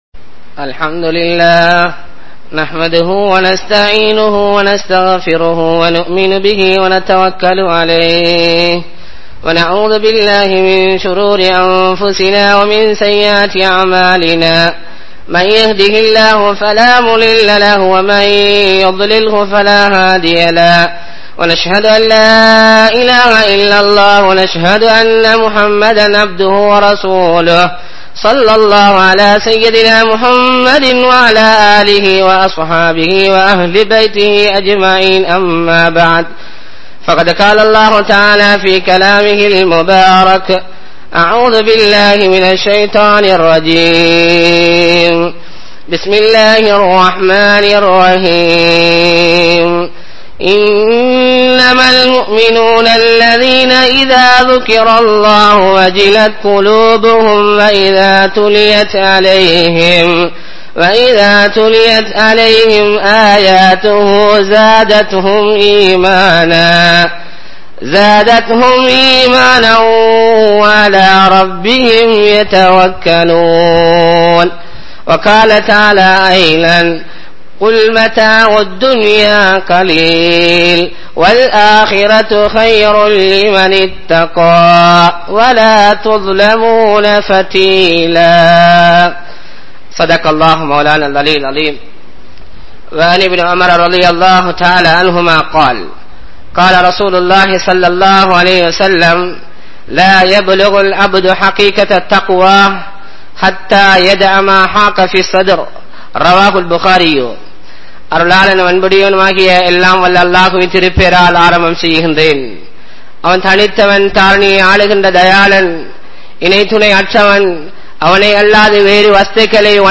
Vifachchaaramum(Zina) Thaqwaavum | Audio Bayans | All Ceylon Muslim Youth Community | Addalaichenai
Muhiyadeen Jumua Masjith